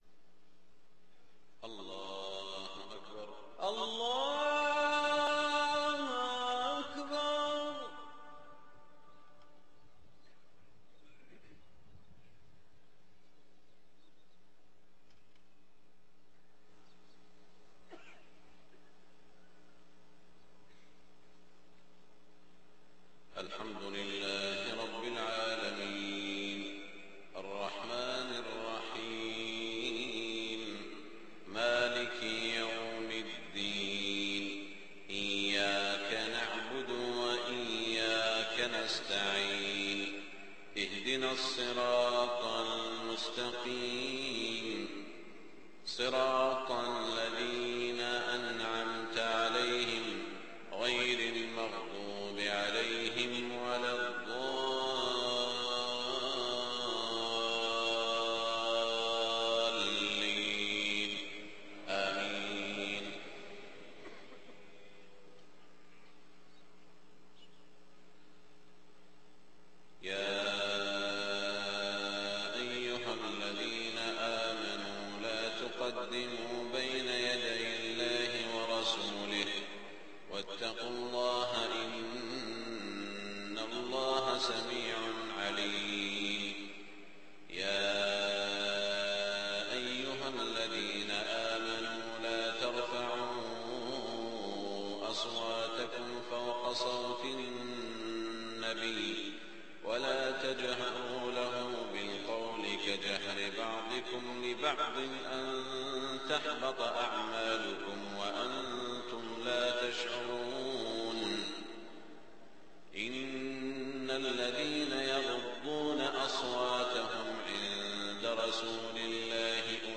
صلاة الفجر 1-2-1428هـ سورة الحجرات > 1428 🕋 > الفروض - تلاوات الحرمين